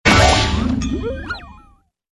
tt_s_ara_cmg_toonHit.ogg